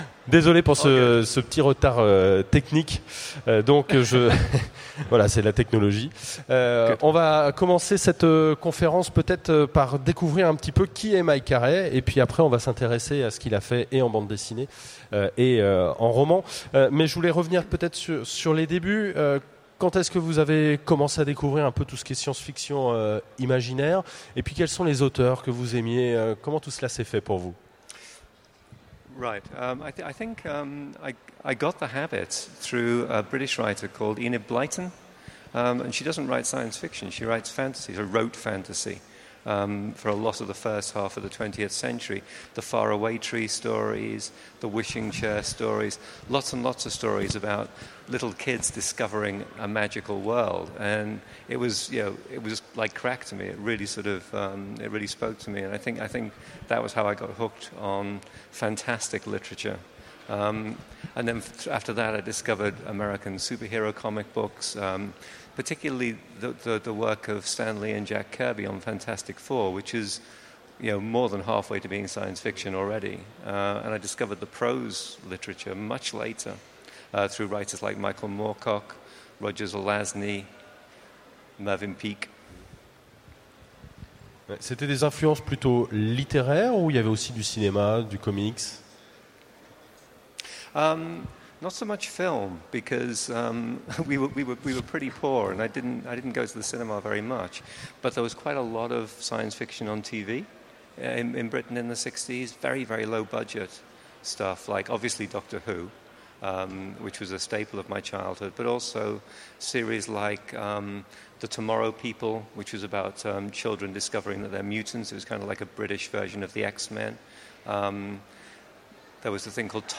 Utopiales 2015 : Rencontre avec M. R. Carey, l’homme qui avait tous les dons
Rencontre avec un auteur Conférence